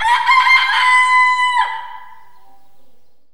Rooster.wav